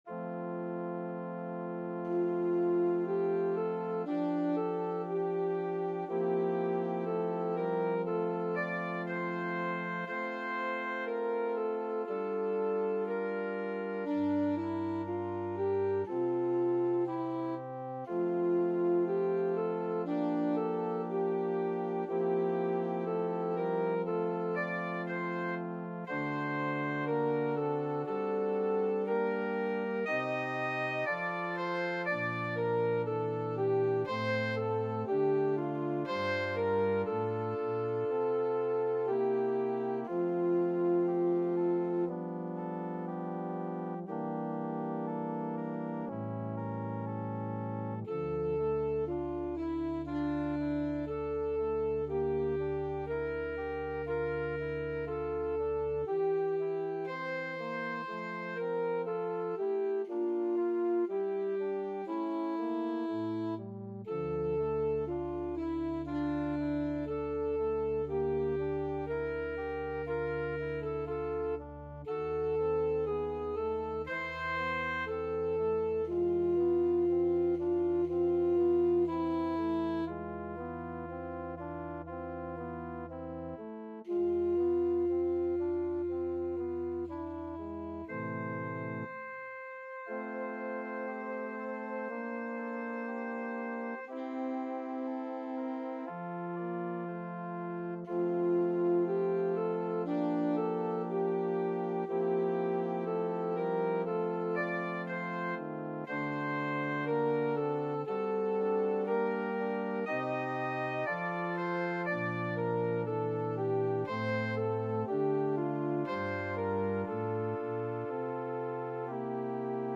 Alto Saxophone
2/4 (View more 2/4 Music)
Classical (View more Classical Saxophone Music)